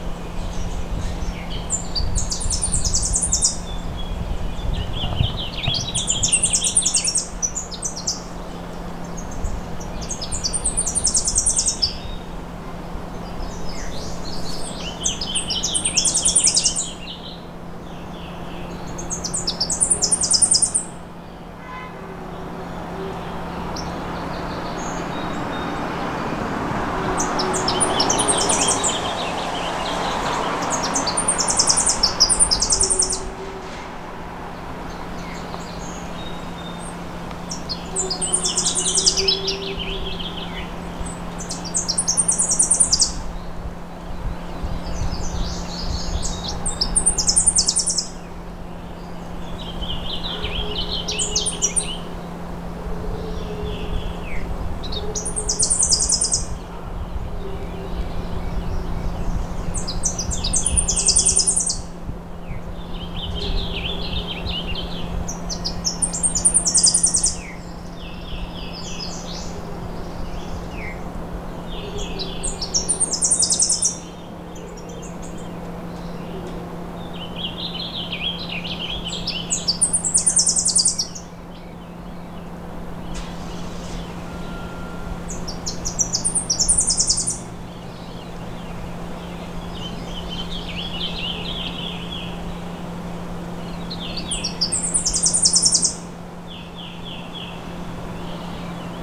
But there’s also beauty in their many and varied sounds. I recorded this singing male Tennessee Warbler in the same place I’d observed the cardinal family: Indian River Park.
Listen for its high-frequency staccato song, and ignore the road noise on Paramont. Also, see if you can ID any of the birds in the background.
tennessee-warbler1.wav